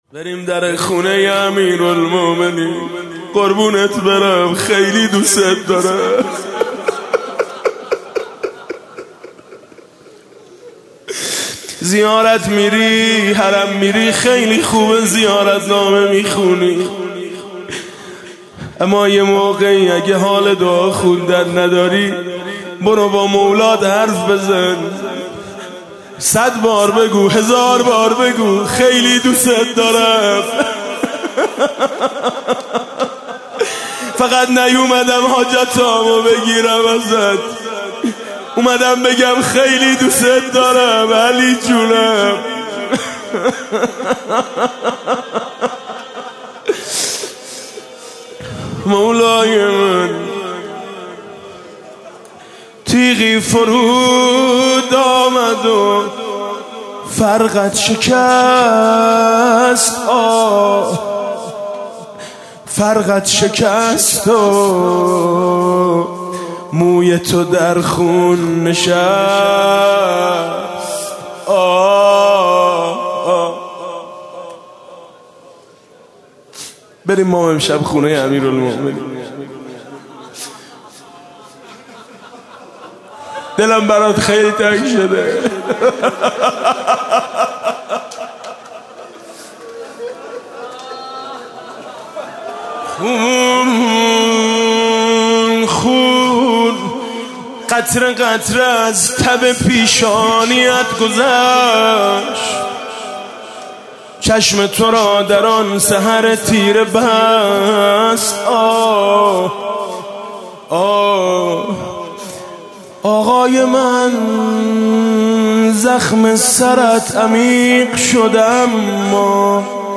20 رمضان 97 - هیئت میثاق با شهدا - روضه - سرت از ضربه‌ی شمشیر به هم ریخته است